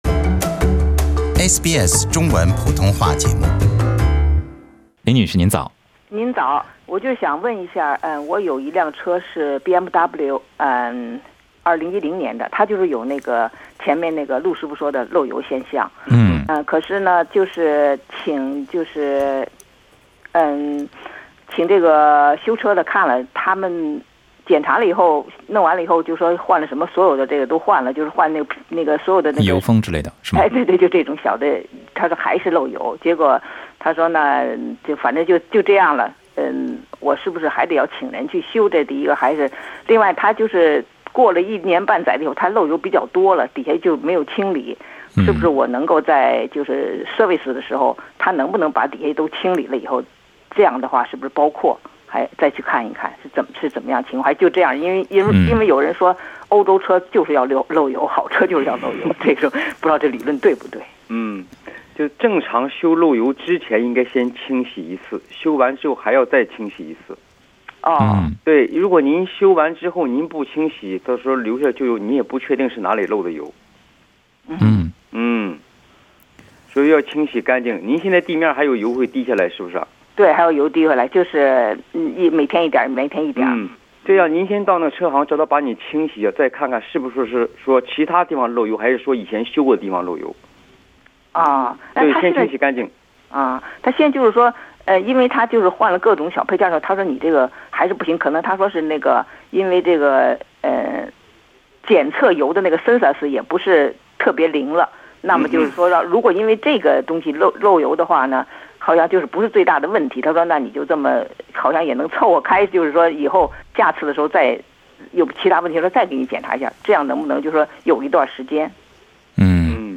07:50 汽車引擎 Source: Pixabay SBS 普通话电台 View Podcast Series Follow and Subscribe Apple Podcasts YouTube Spotify Download (14.37MB) Download the SBS Audio app Available on iOS and Android 怎么样区分汽车发动机渗油和漏油？